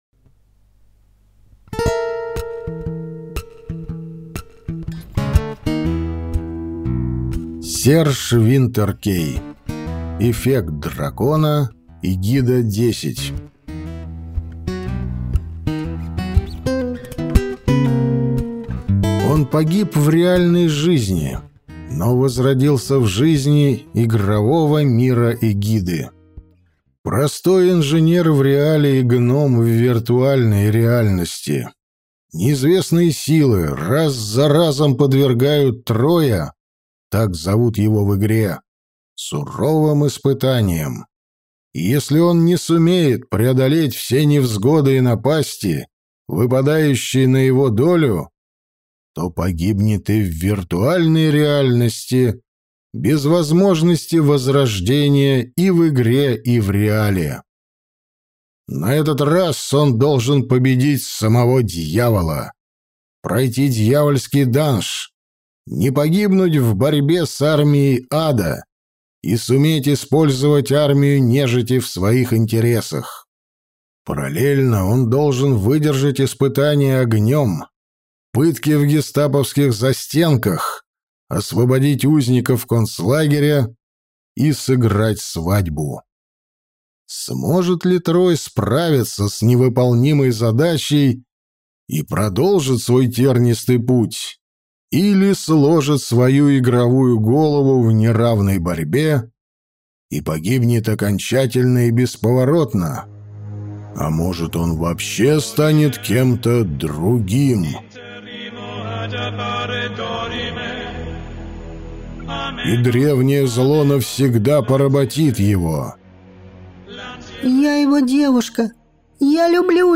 Аудиокнига Эффект дракона | Библиотека аудиокниг